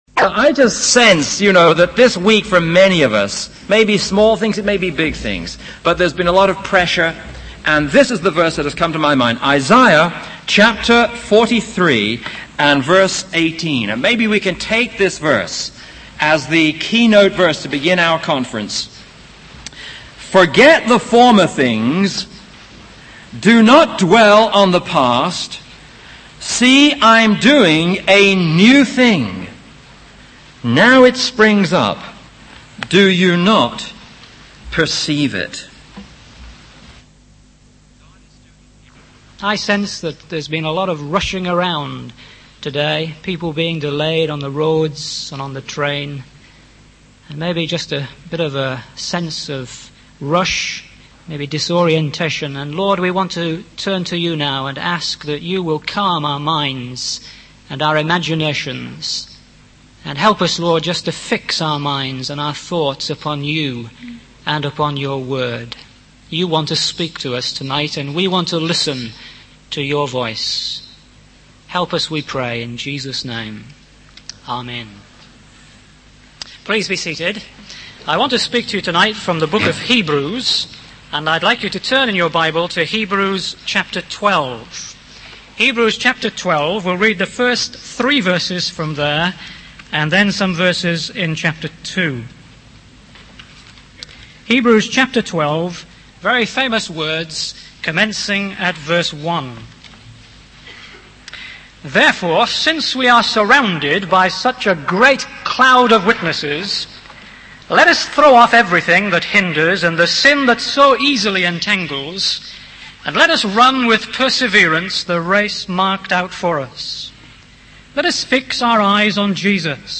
In this sermon, the speaker emphasizes the importance of Jesus as a role model for leaders. He highlights how Jesus' actions matched his words, which made him incredibly impactful and inspiring to others.